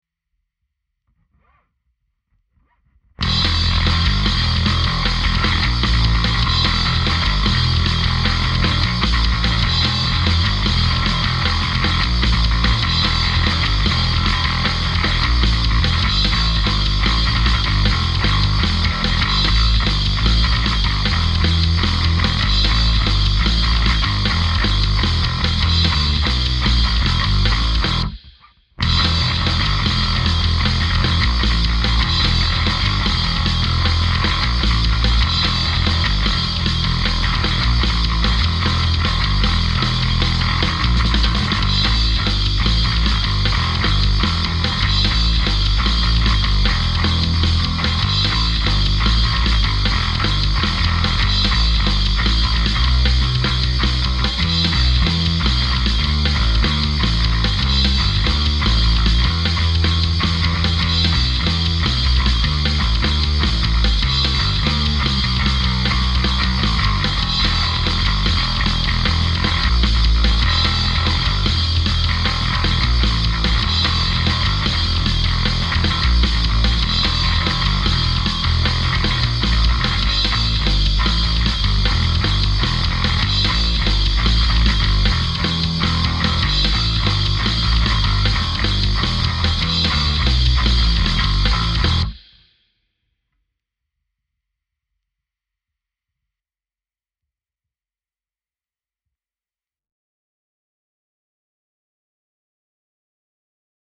Menace – Boom Box demo